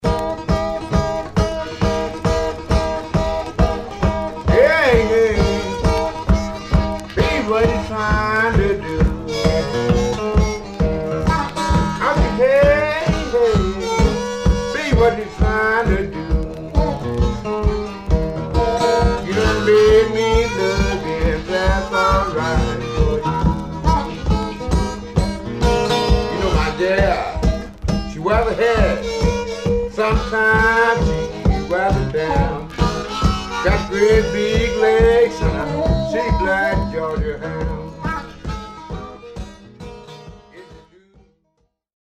Mono
Rythm and Blues